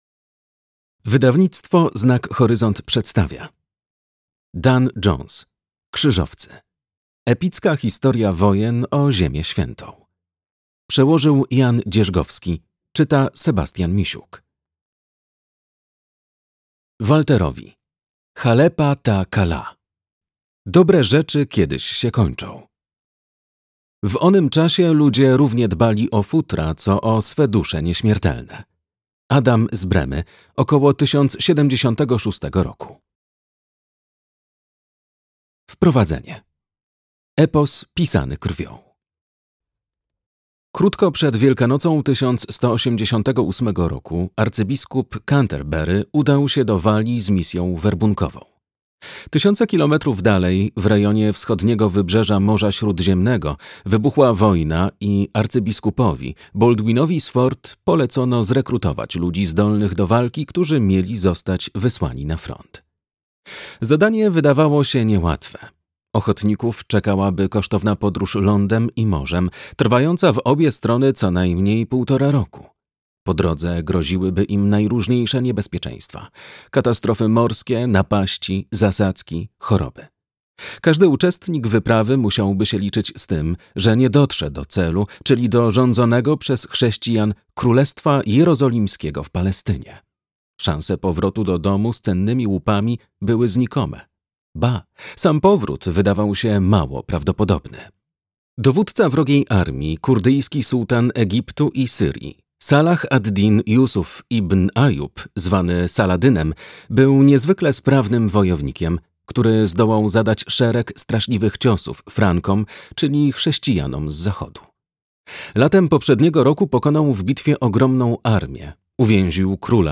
Krzyżowcy - Jones Dan - audiobook